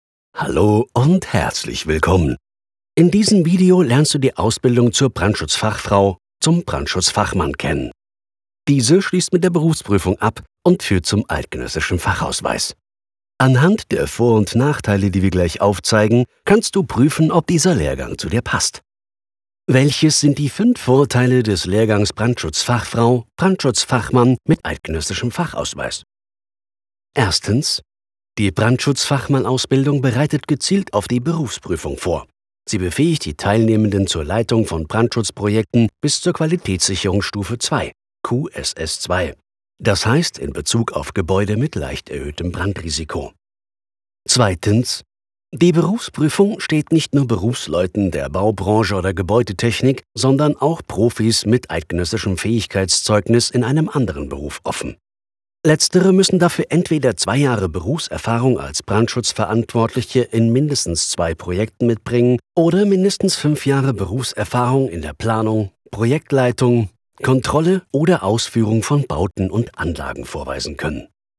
markant, sonore Stimme, kernig Stimmalter: 35-65
Sprechprobe: eLearning (Muttersprache):
If you are looking for a distinctive, sonorous voice, then you have come to the right place.